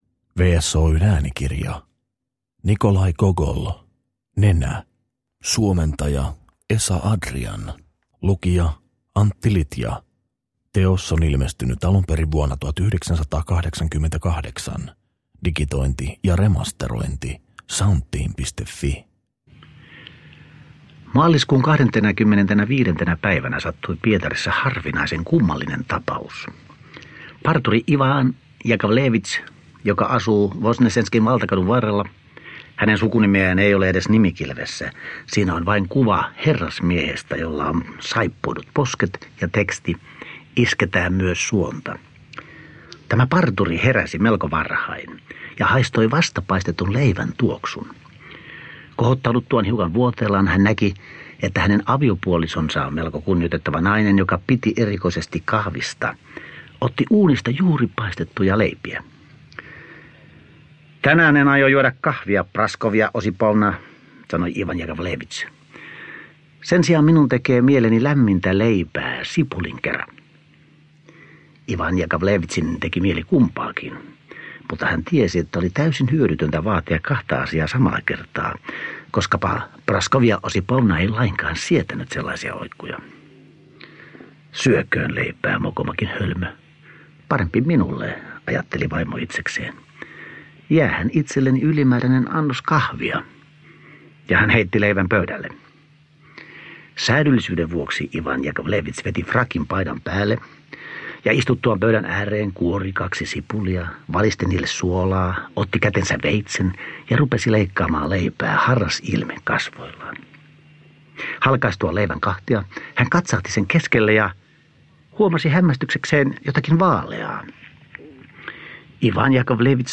Nenä – Ljudbok – Laddas ner
Uppläsare: Antti Litja